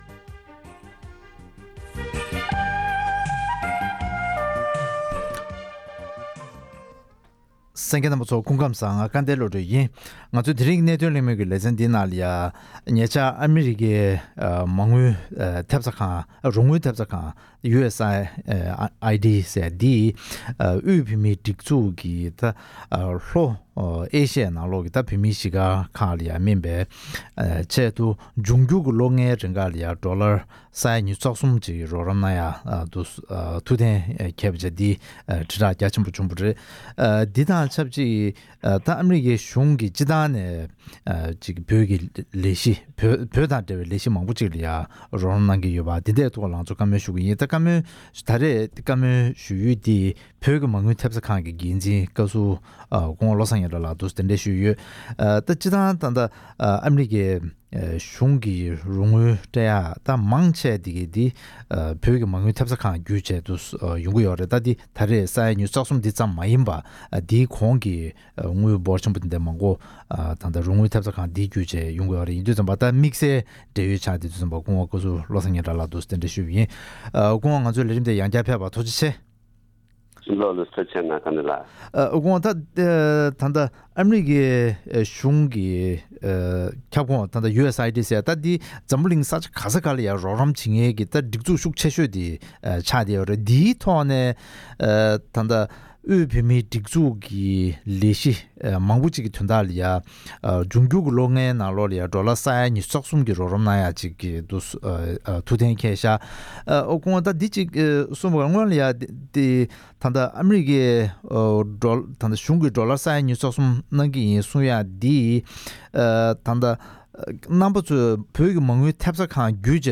དབུས་བོད་མིའི་སྒྲིག་འཛུགས་ལ་ཨ་རི་གཞུང་གི་རོགས་རམ་ཐེབས་རྩ་ཁང་ USAID ཞེས་པ་བརྒྱུད་ཨ་སྒོར་ས་ཡ་ཉི་ཤུ་རྩ་གསུམ་ལོ་ལྔའི་རིང་གནང་རྒྱུ་དང་འབྲེལ་བའི་སྐོར་གླེང་མོལ།